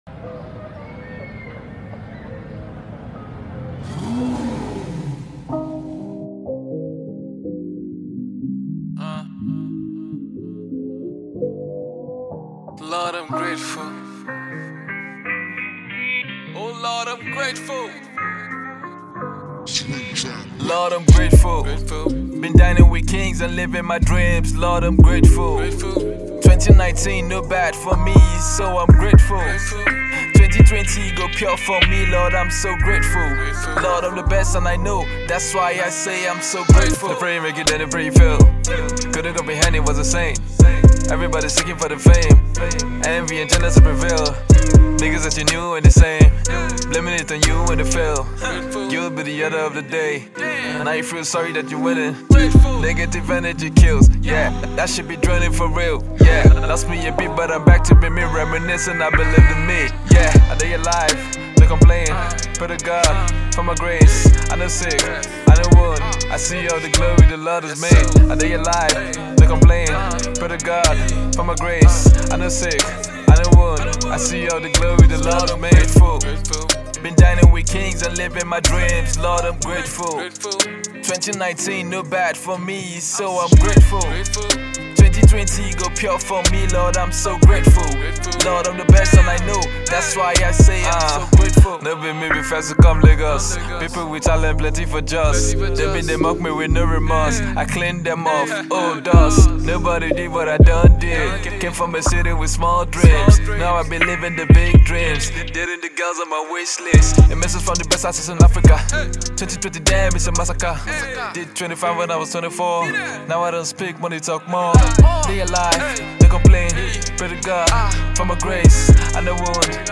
banging new jam